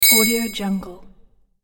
دانلود افکت صدای دینگ شیشه
Sample rate 16-Bit Stereo, 44.1 kHz
Looped No